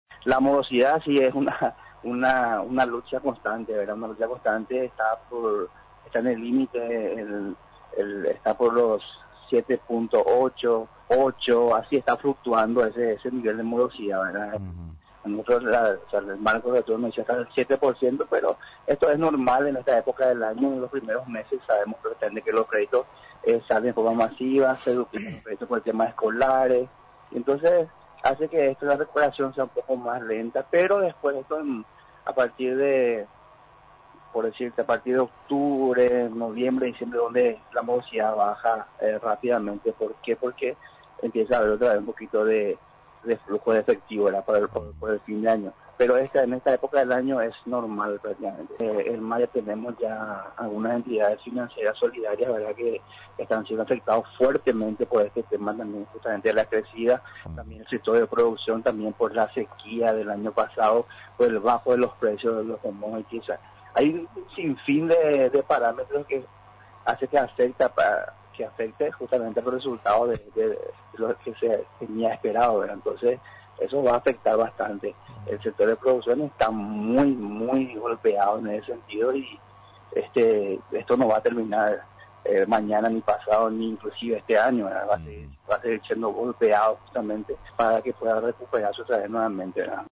El presidente del Instituto Nacional de Cooperativas (INCOOP), explicó este lunes que es normal en esta época del año, el registro de un 8 por ciento de morosidad, debido a las compras de útiles escolares, uniformes y matrículas.